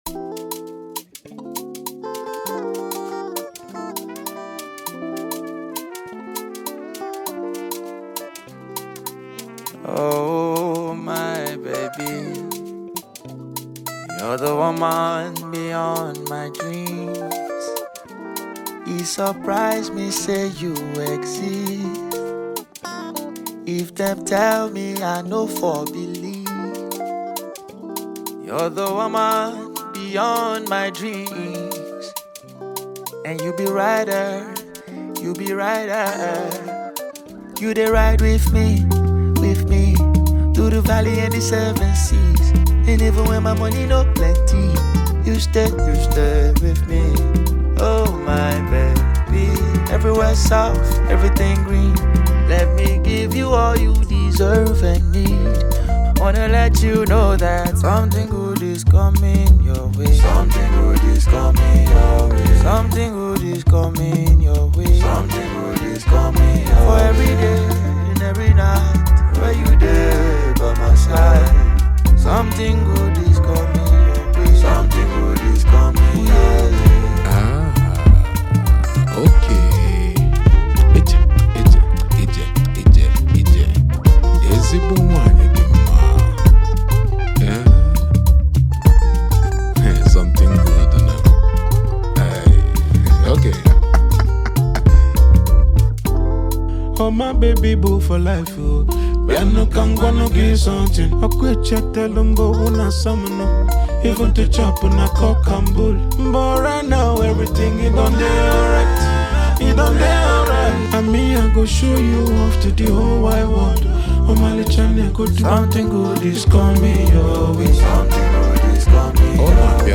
March 31, 2025 Publisher 01 Gospel 0